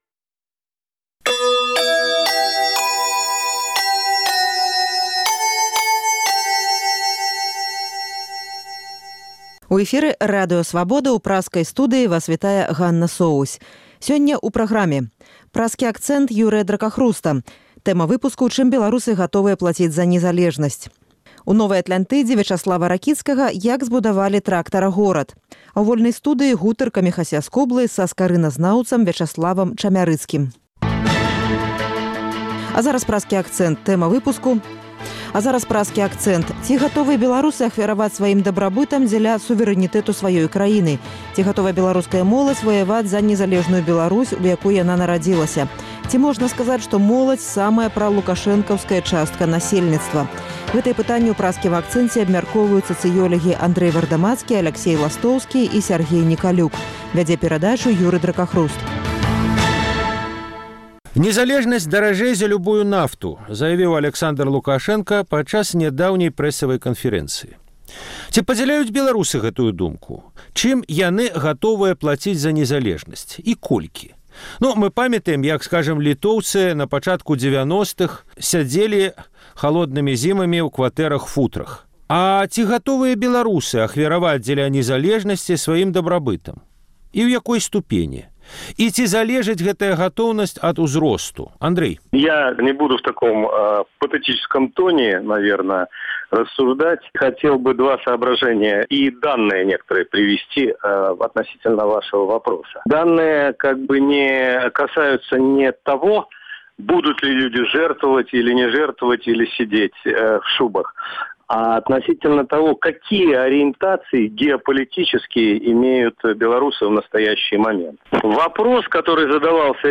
Гэтыя пытаньні ў Праскім акцэнце абмяркоўваюць сацыёлягі